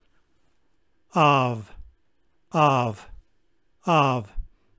In the Koine Greek era the Upsilon shifted to a consonantal \v\ sound in the vowel combinations αυ <